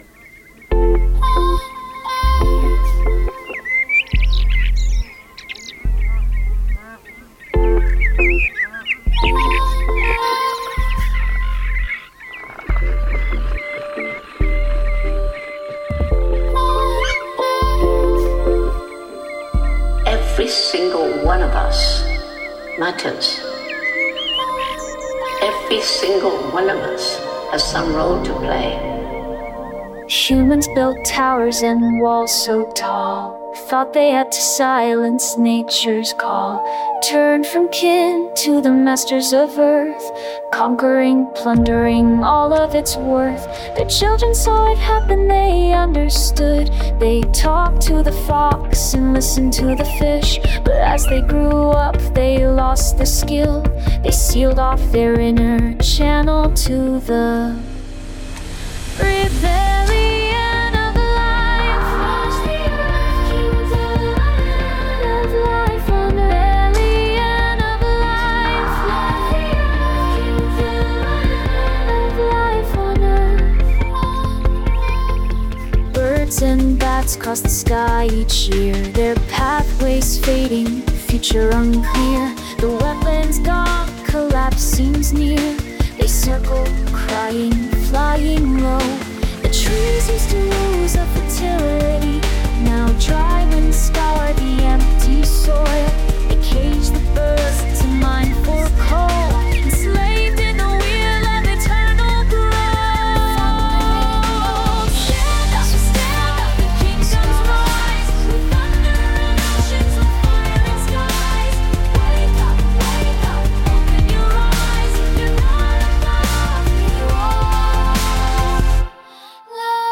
A lyrical ‘story-room’ becomes a choral call to life, stitched with Jane Goodall’s reminder that every being matters.
An original piece woven into the episode